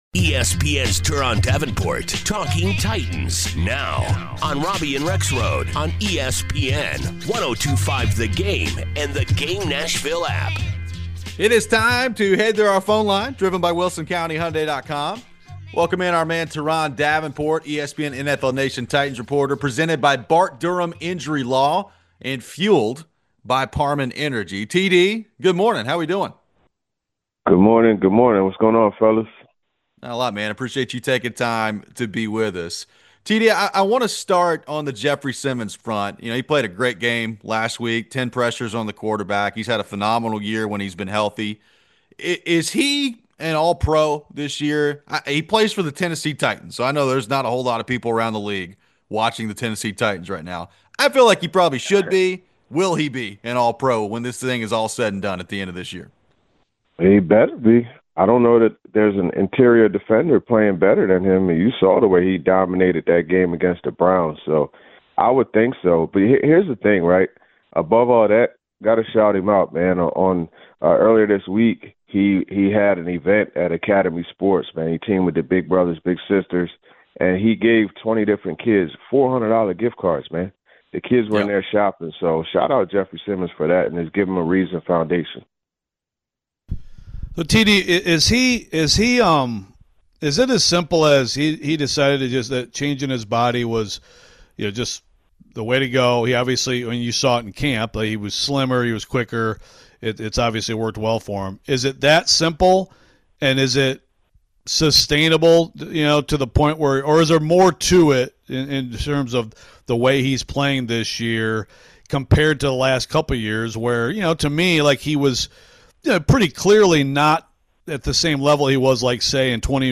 We head to the phones. What do we think the NFL prospects are for Diego Pavia?